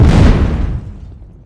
CannonEx.ogg